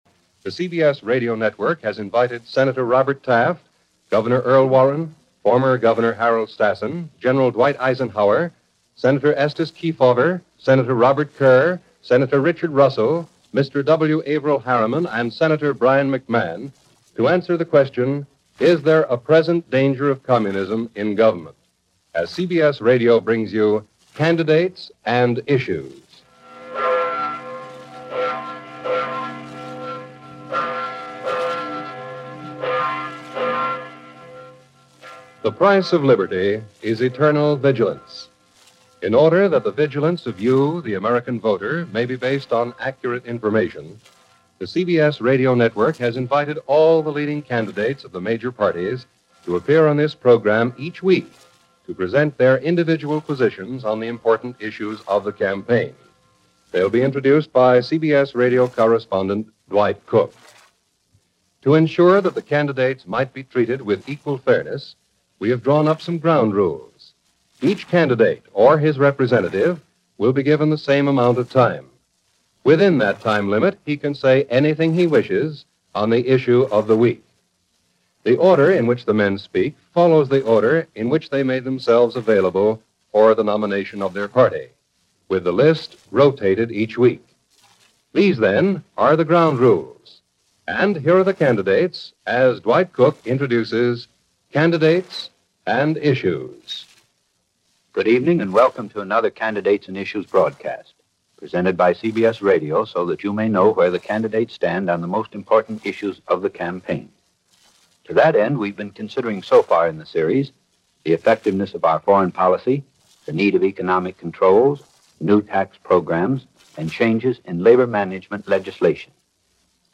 Communist Influence In Government - Candidates And Issues - May 13, 1952 - Presidential candidates discussion on Red Scare.